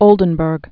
(ōldən-bûrg, -brk)